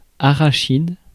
Ääntäminen
US : IPA : [piː.nʌt]